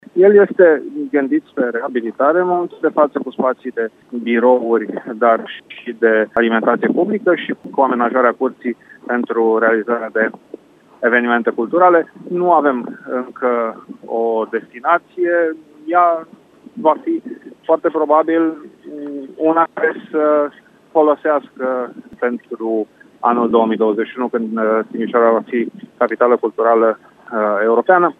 Viceprimarul Dan Diaconu spune că spațiul va avea atât birouri cât și unități de alimentație publică, urmând să deservească și pentru organizarea de evenimente culturale.